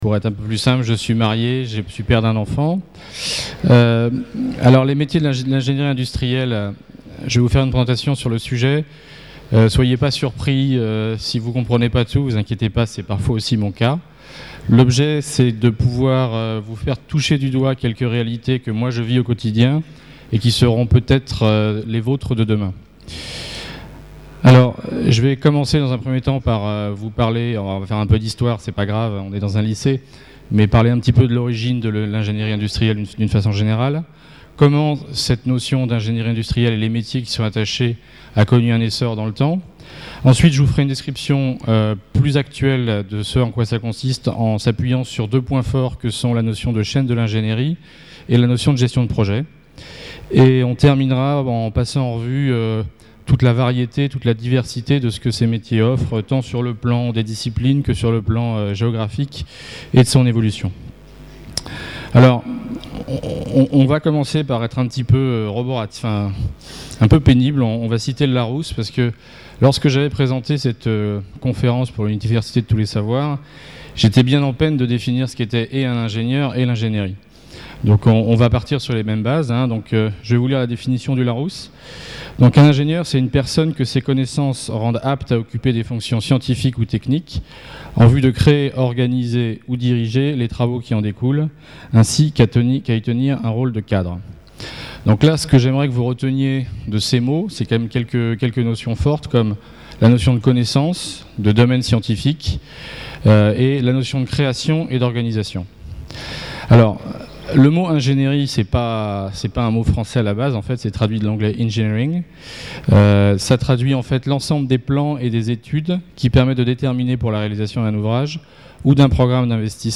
Une conférence de l'UTLS au lycée Lycée Langevin (83 La Seyne sur Mer)